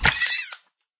land_death.ogg